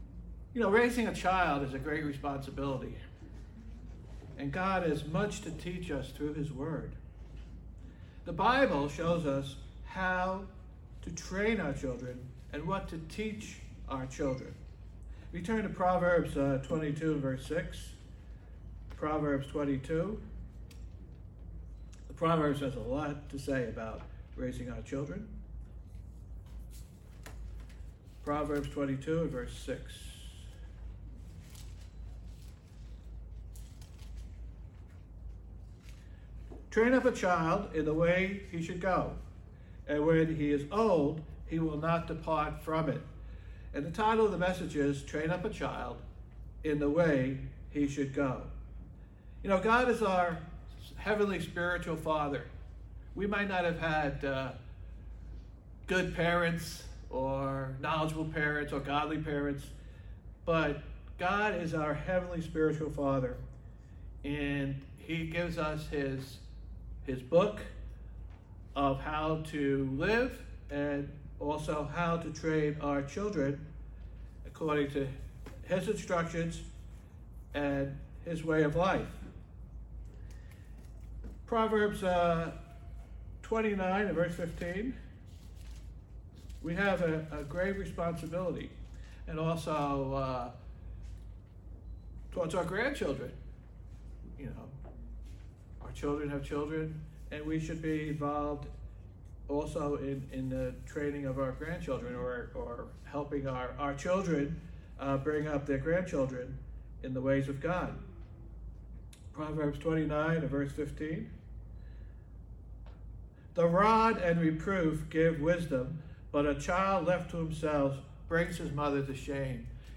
Sermons
Given in New Jersey - North New York City, NY